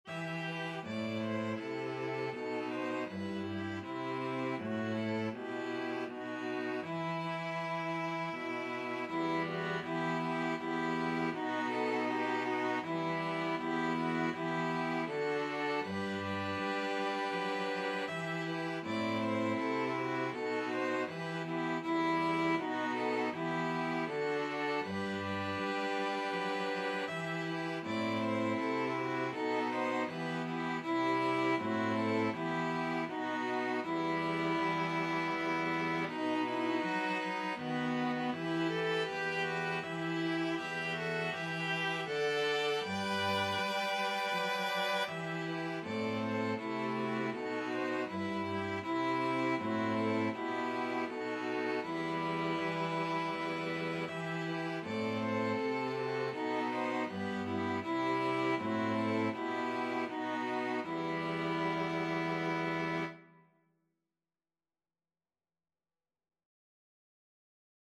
Violin 1Violin 2ViolaCello
E minor (Sounding Pitch) (View more E minor Music for String Quartet )
3/4 (View more 3/4 Music)
Andante
String Quartet  (View more Easy String Quartet Music)
Classical (View more Classical String Quartet Music)